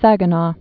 (săgə-nô)